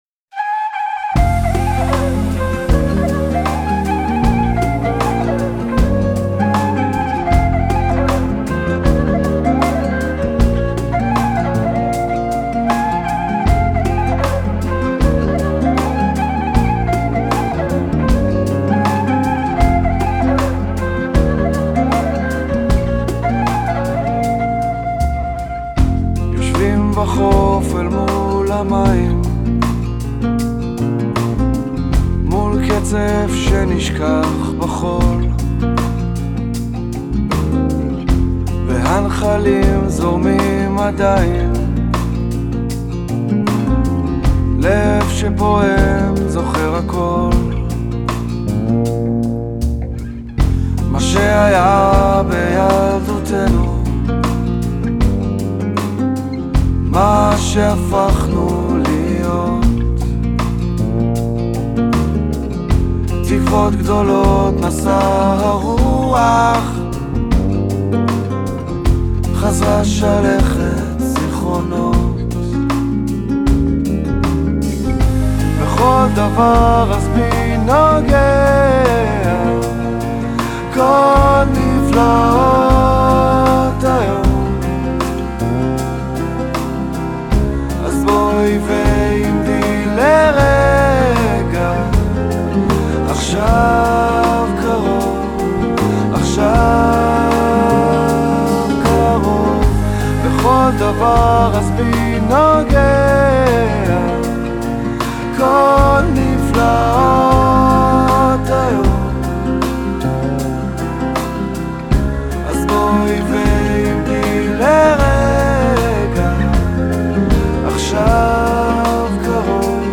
Genre: Worl Music, Israel, Pop, Folk